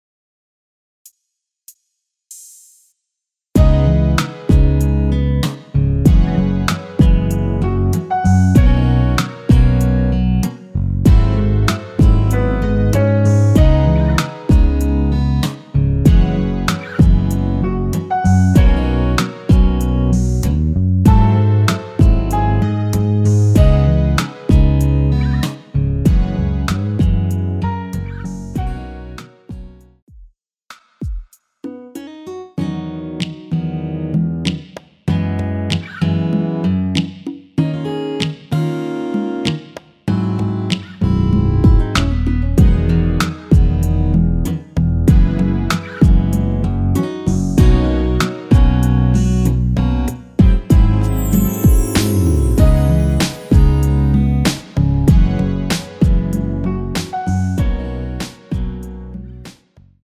엔딩이 페이드 아웃이라 엔딩을 만들어 놓았습니다.
C#m
◈ 곡명 옆 (-1)은 반음 내림, (+1)은 반음 올림 입니다.
앞부분30초, 뒷부분30초씩 편집해서 올려 드리고 있습니다.